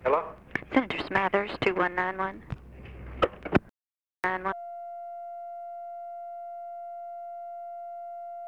Conversation with GEORGE SMATHERS, December 12, 1963
Secret White House Tapes